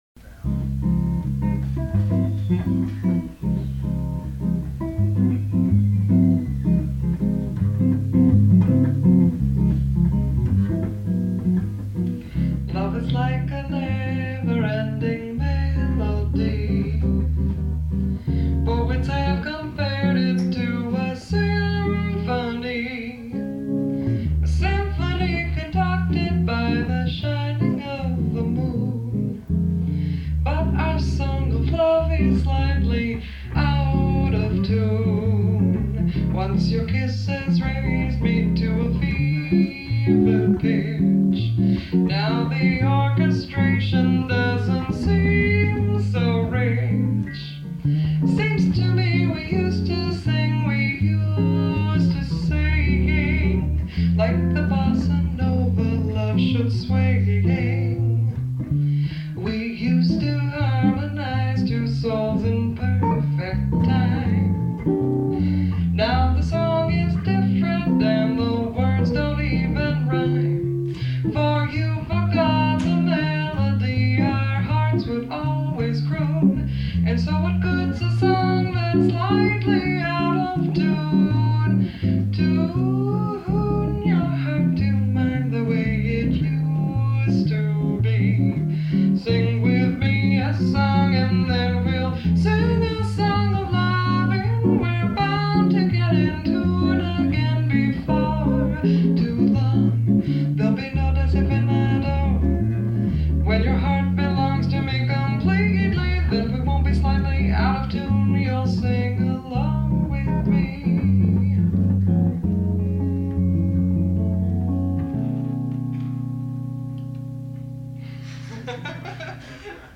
Ted Greene "Guitar Institute of Technology" Seminar - 1978